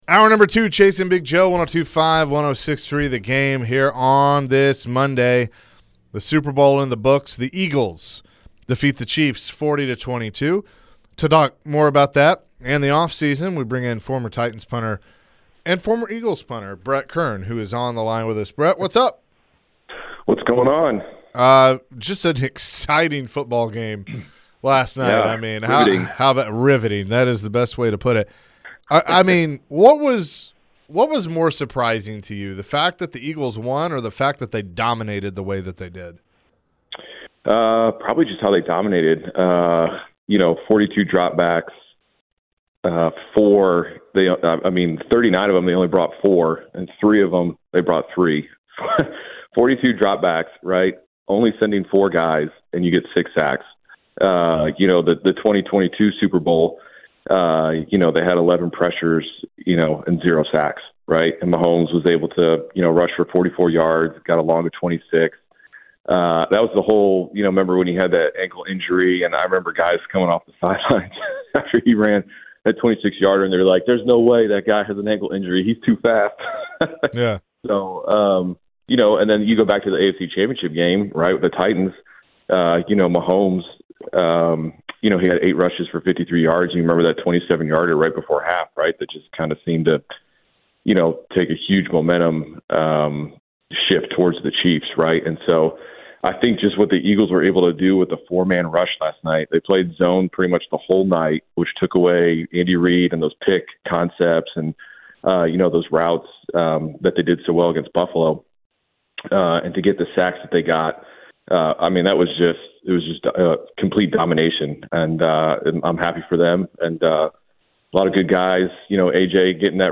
Former Titans Punter Brett Kern joined the show and shared his thoughts on the Eagles winning the Super Bowl. Brett previously played for the Eagles after the Titans and went to the Super Bowl two years ago.